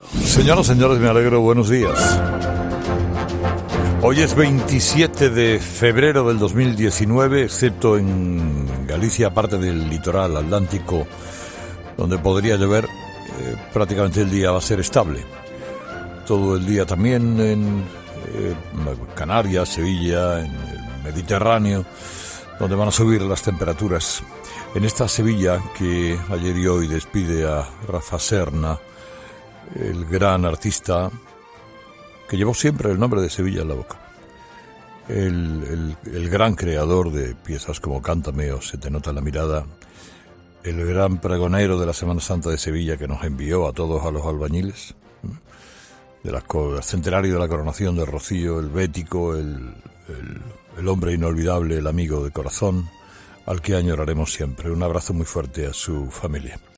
Carlos Herrera ha querido rendir su más íntimo homenaje al cantautor en su editorial de este miércoles: “Sevilla despide a Rafa Serna, el gran artista que llevó siempre el nombre de Sevilla en la boca”.
“Un abrazo muy fuerte a su familia”, se despedía entre suspiros Herrera.